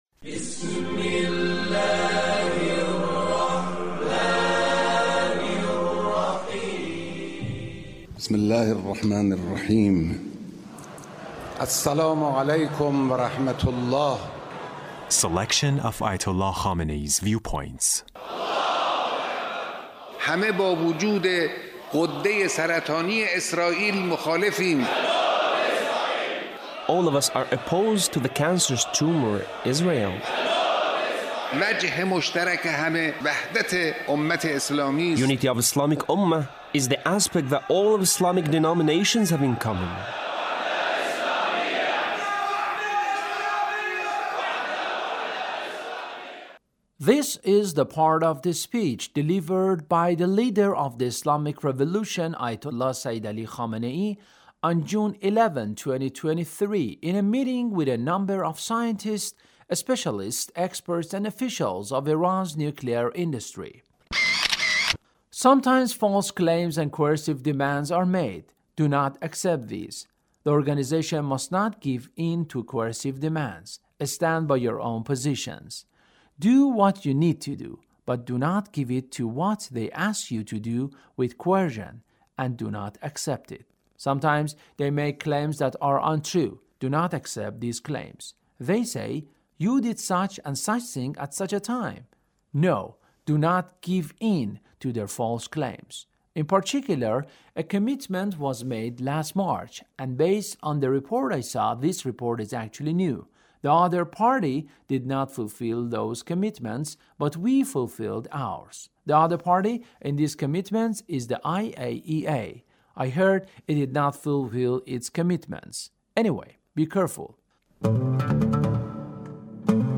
Leader's Speech in a meeting with the commanders, crew, and families of the 86th Flotilla of the Islamic Republic of Iran Navy.